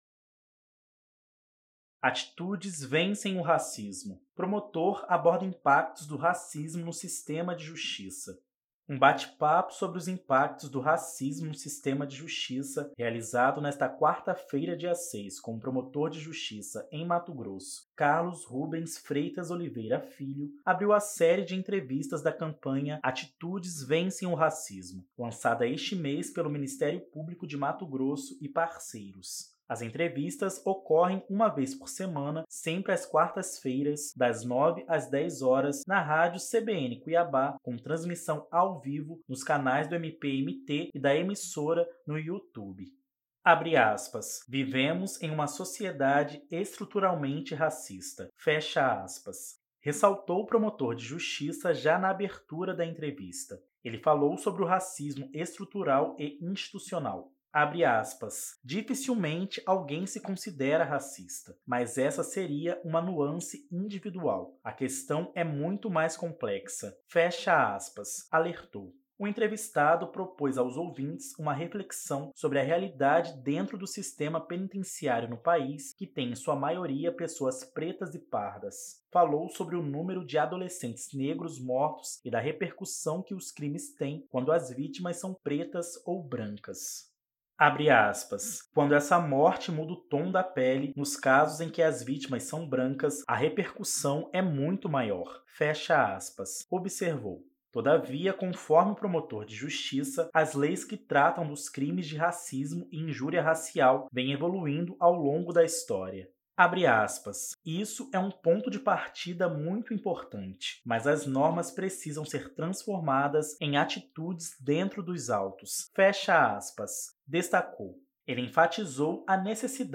Um bate-papo sobre os impactos do racismo no sistema de Justiça, realizado nesta quarta-feira (06) com o promotor de Justiça em Mato Grosso Carlos Rubens Freitas Oliveira Filho, abriu a série de entrevistas da campanha “Atitudes vencem o Racismo”, lançada este mês pelo Ministério Público do Estado de Mato Grosso e parceiros. As entrevistas ocorrem uma vez por semana, sempre às quartas-feiras, das 9h às 10h, na Rádio CBN Cuiabá, com transmissão ao vivo nos canais do MPMT e da emissora no YouTube.